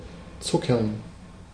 Ääntäminen
Ääntäminen Tuntematon aksentti: IPA: /ˈtsʊkɐn/ Haettu sana löytyi näillä lähdekielillä: saksa Käännöksiä ei löytynyt valitulle kohdekielelle.